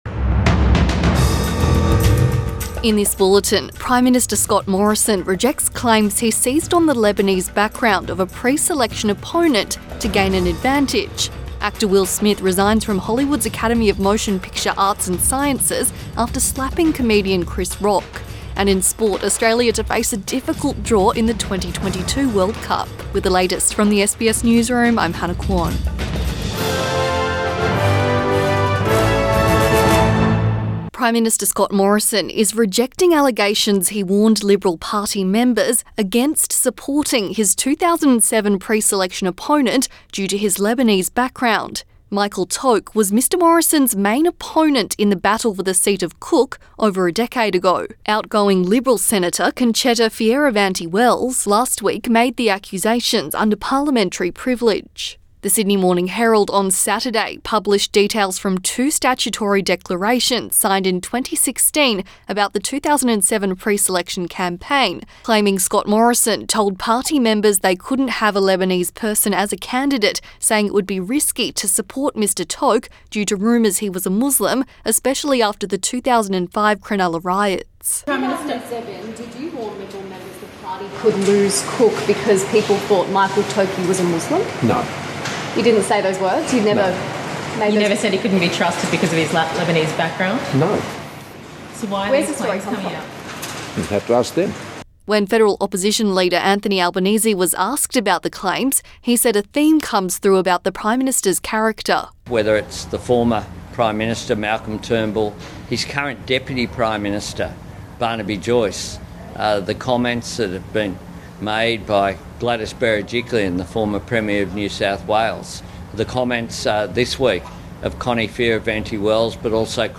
PM bulletin 2 April 2022